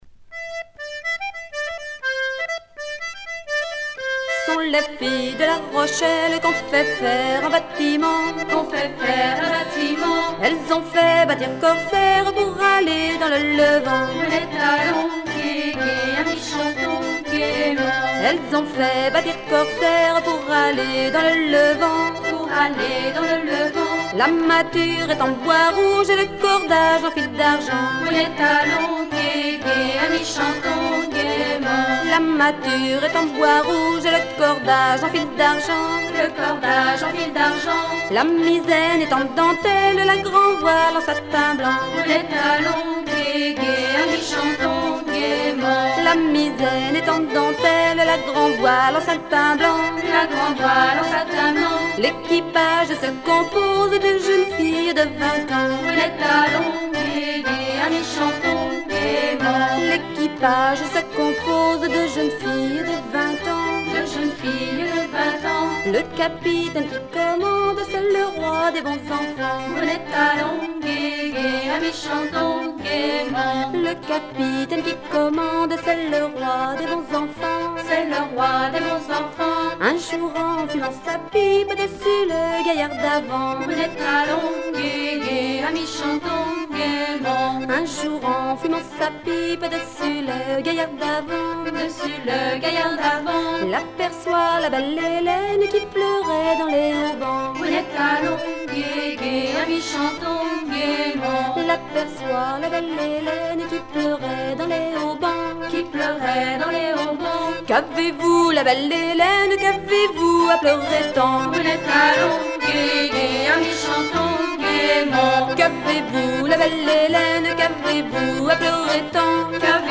danse : ronde à trois pas
circonstance : maritimes
Pièce musicale éditée